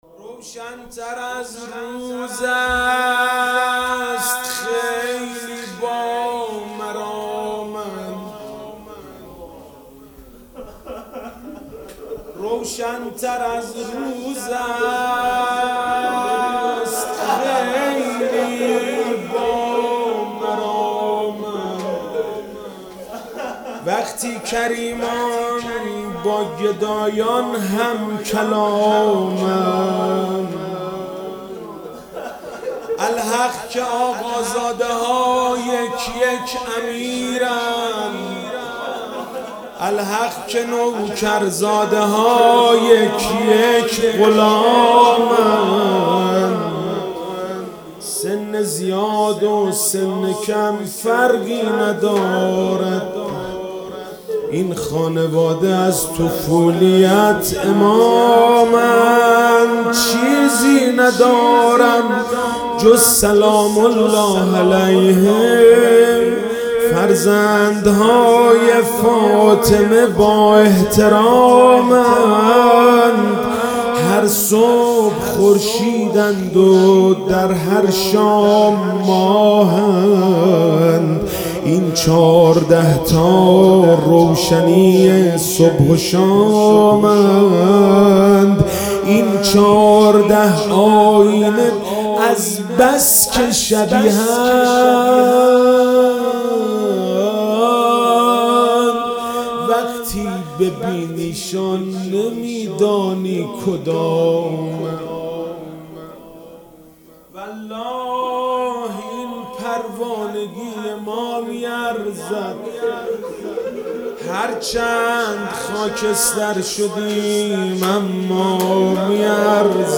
رجب99_ولادت امام جواد و علی اصغر